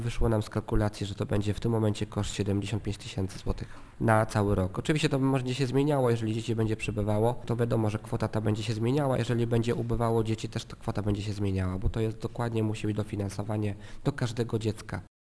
Z informacji od prowadzących żłobki i klub dziecięcy wynika, że obecnie przebywa w nich 28 dzieci, co oznacza, że wysokość dotacji na pewno nie wykracza poza możliwości budżetowe miasta - uważa burmistrz Muszyński: